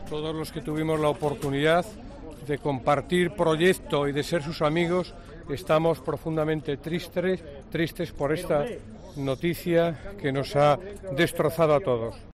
Escucha a José Blanco, ex secretario de Organización del PSOE y exministro de Fomento